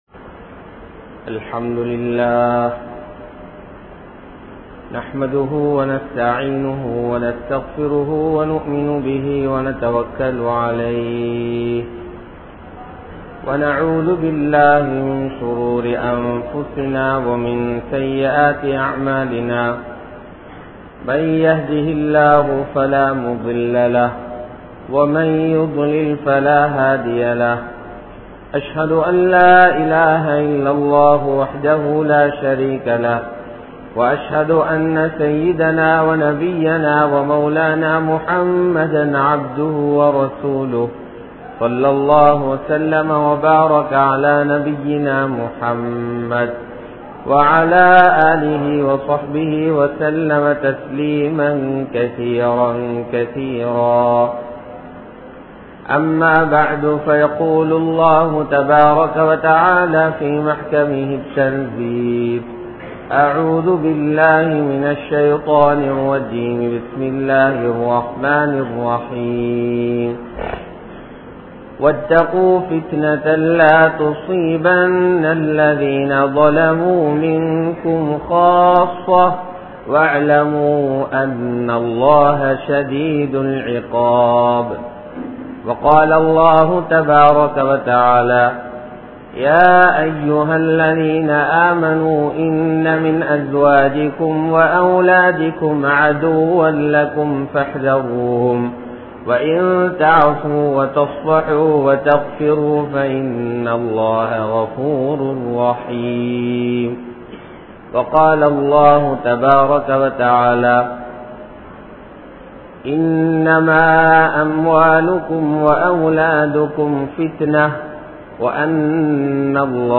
Dhajjaal Entraal Yaar? (தஜ்ஜால் என்றால் யார்?) | Audio Bayans | All Ceylon Muslim Youth Community | Addalaichenai